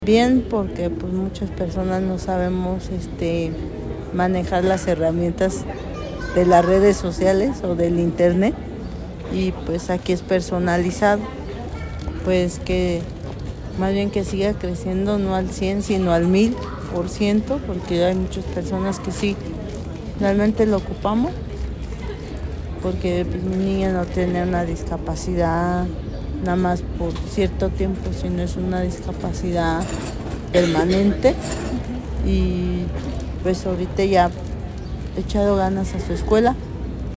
AudioBoletines